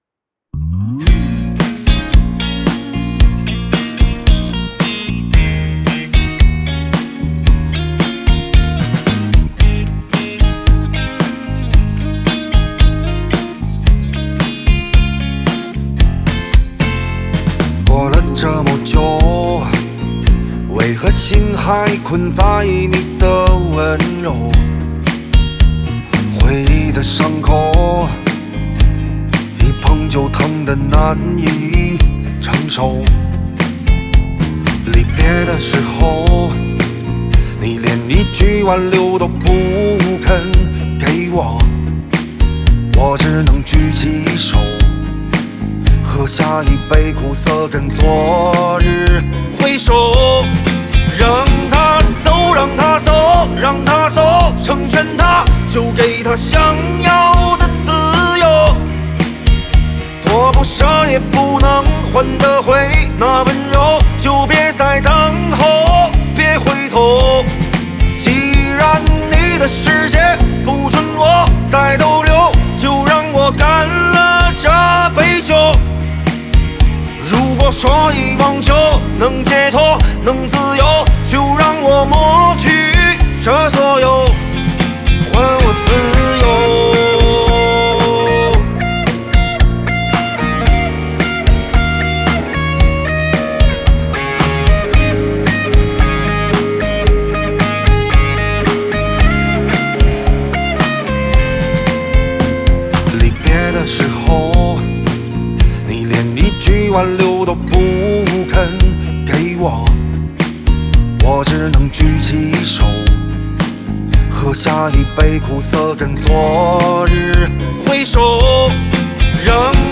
Ps：在线试听为压缩音质节选，体验无损音质请下载完整版
吉他